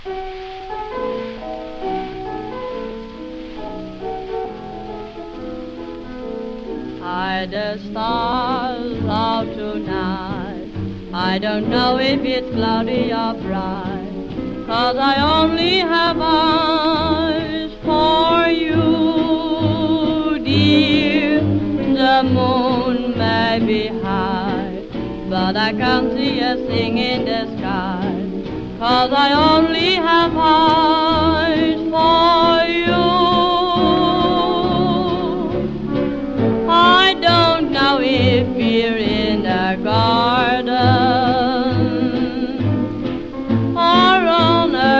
Jazz, Big Band　Netherlands　12inchレコード　33rpm　Mono